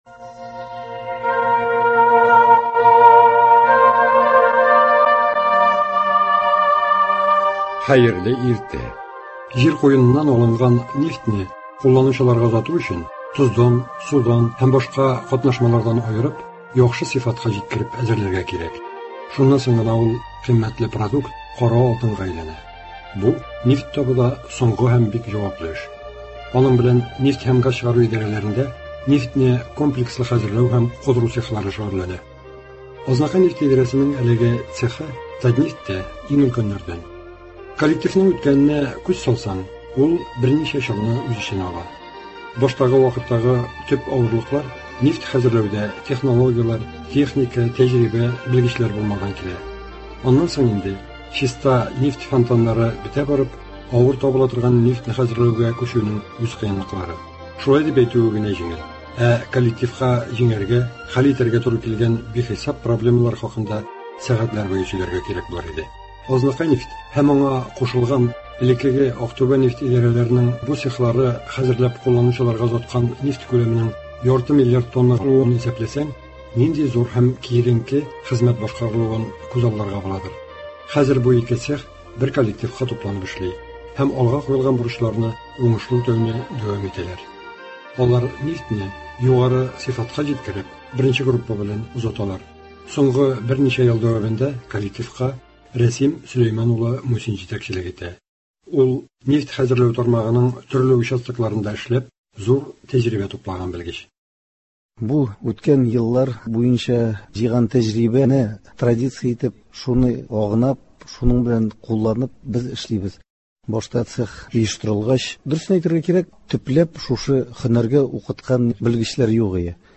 «Азнакайнефть» идарәсенең нефьне комплекслы хәзерләү һәм кудыру цехы «Татнефть»тә иң өлкәннәрдән. Цех ветераннары белән әңгәмә.